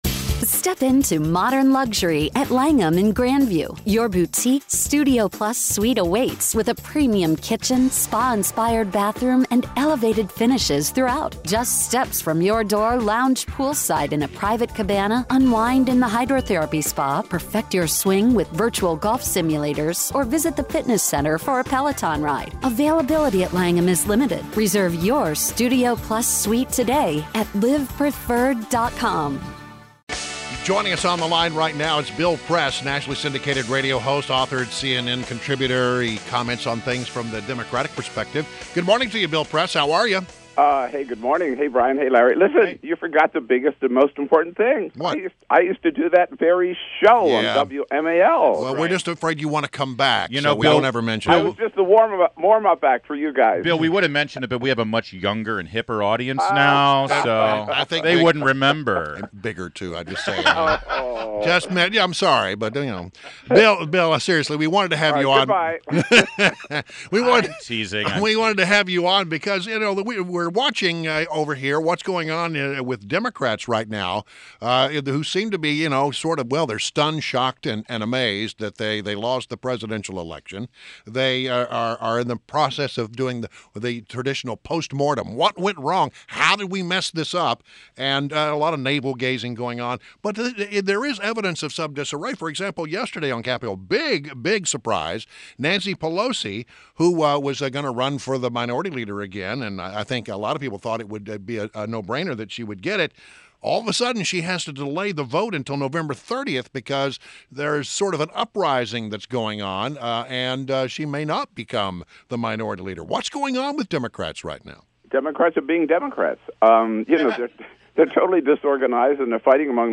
INTERVIEW -- BILL PRESS - Nationally syndicated radio host, author of "Buyer's Remorse", CNN contributor and former WMAL host